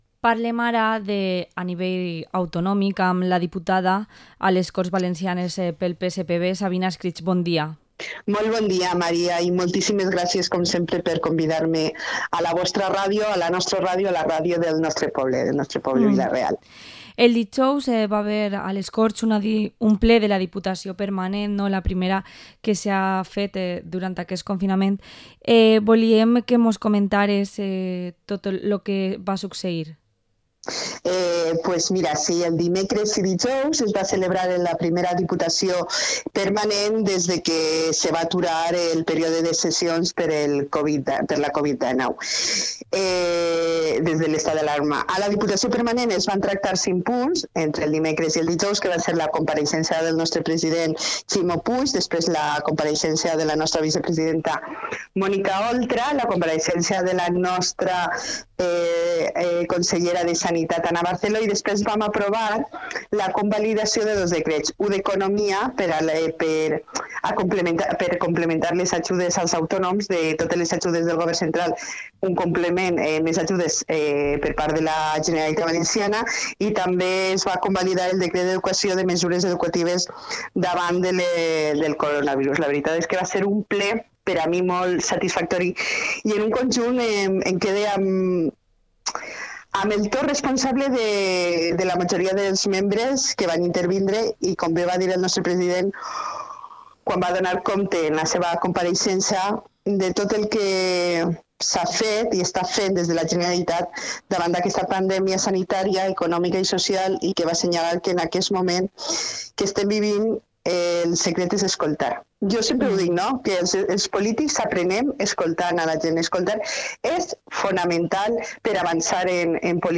Entrevista a la diputada autonómica y portavoz adjunta del grupo parlamentario socialista a las Cortes Valencianas, Sabina Escrig